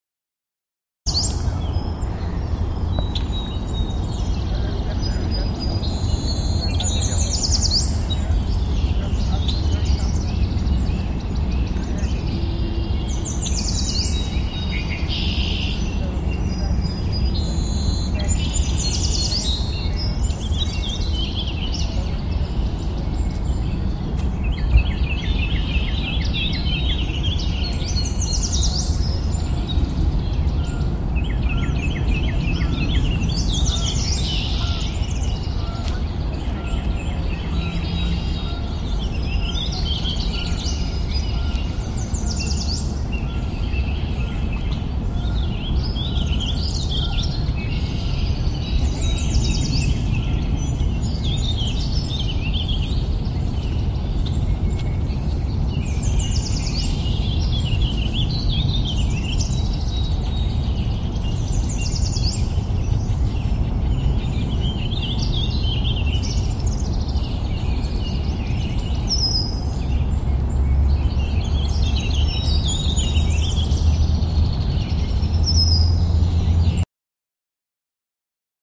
natureza.mp3